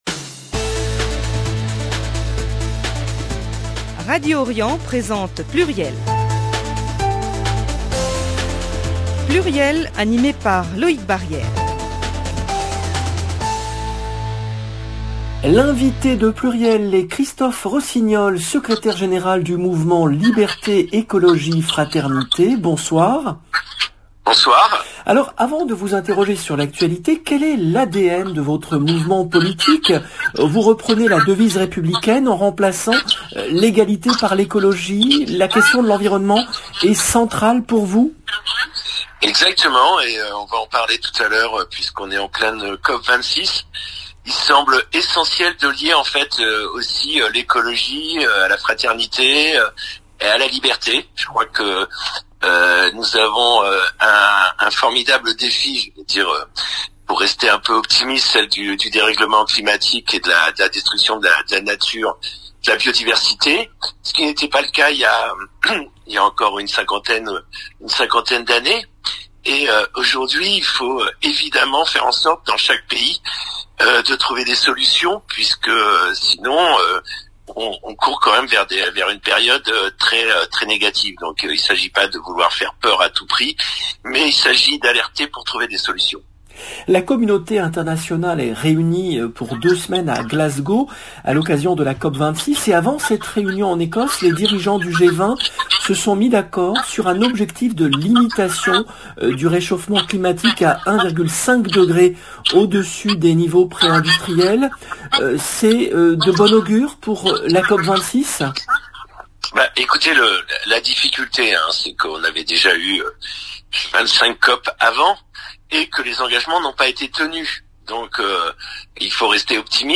Est-ce de bon augure pour la coop 26 ? Comment répondre à la société du jetable et à l'obselence programmée ? Emission présentée par